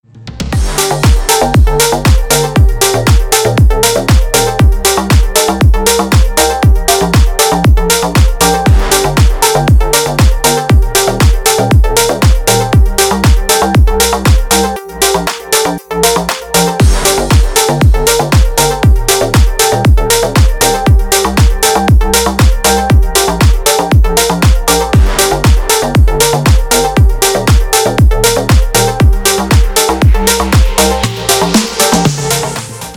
Клубные новинки на рингтон
• Песня: Рингтон, нарезка